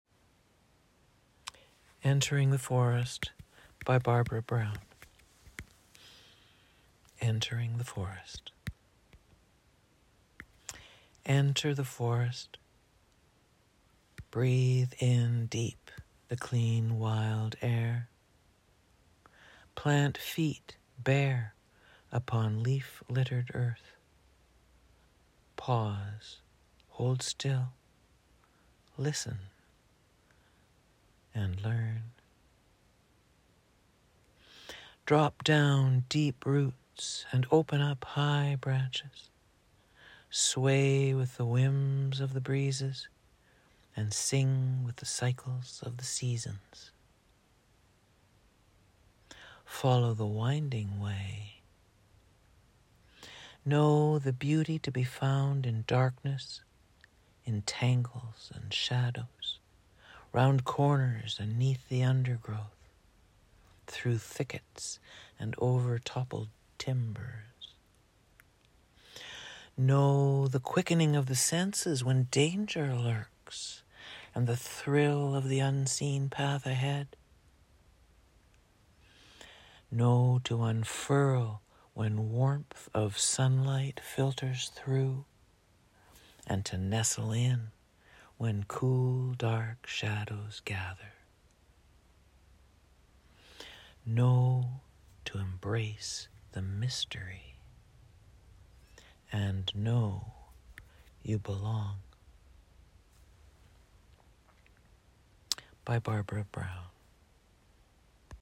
Poem Audio Recording